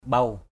/ɓau/